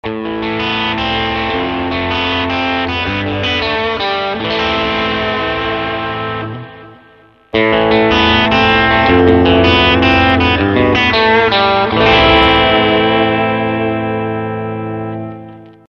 The Tubescreamer is quite possibly one of the
most used overdrive pedals to come along. It
(played 1st stock, then w/mod)
CAREFULL, this is a much louder
and "Meaner" overdrive than the